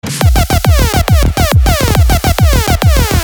• Качество: 320, Stereo
громкие
Electronic
электронная музыка
без слов
взрывные
Trance